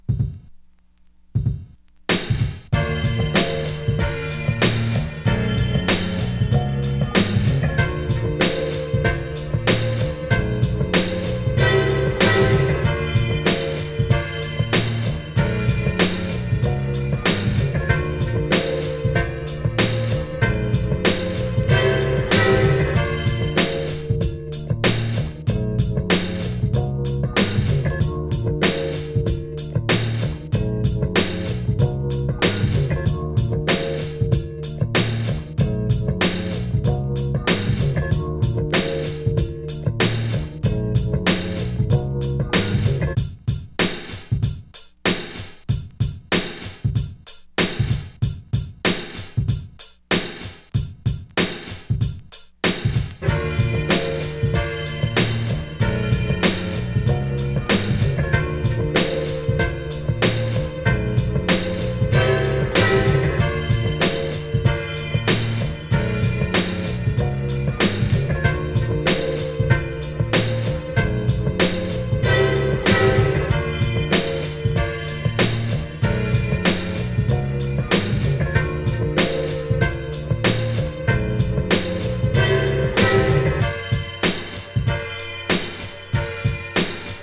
ワサワサした雰囲気のマイクリレー